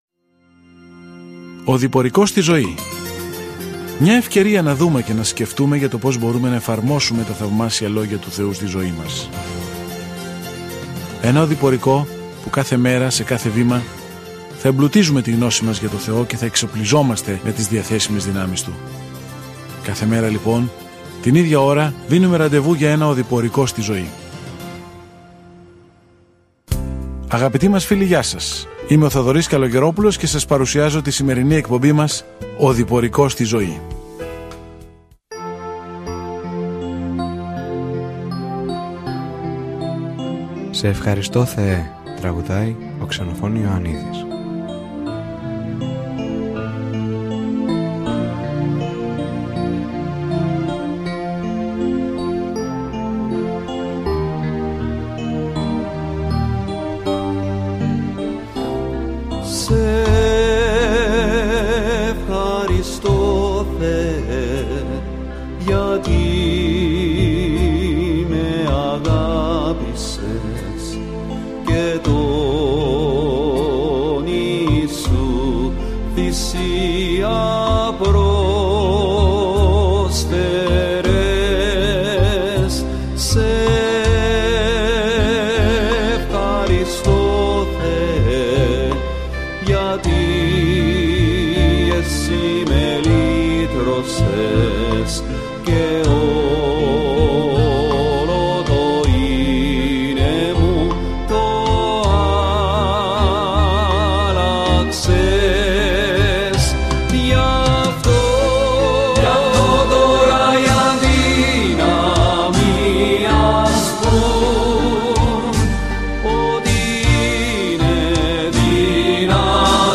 Κείμενο ΠΡΟΣ ΓΑΛΑΤΑΣ 6:5-7 Ημέρα 17 Έναρξη αυτού του σχεδίου Ημέρα 19 Σχετικά με αυτό το σχέδιο «Μόνο με πίστη» σωζόμαστε, όχι με οτιδήποτε κάνουμε για να αξίζουμε το δώρο της σωτηρίας - αυτό είναι το σαφές και άμεσο μήνυμα της επιστολής προς τους Γαλάτες. Καθημερινά ταξιδεύετε στους Γαλάτες καθώς ακούτε την ηχητική μελέτη και διαβάζετε επιλεγμένους στίχους από τον λόγο του Θεού.